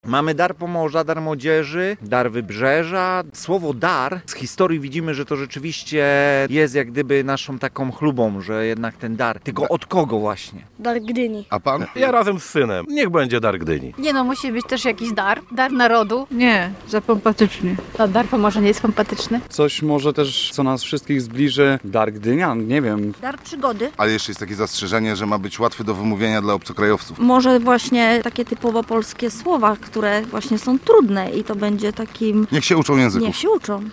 Wymyśl imię dla nowego żaglowca [SONDA]